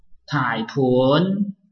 臺灣客語拼音學習網-客語聽讀拼-海陸腔-鼻尾韻
拼音查詢：【海陸腔】pun ~請點選不同聲調拼音聽聽看!(例字漢字部分屬參考性質)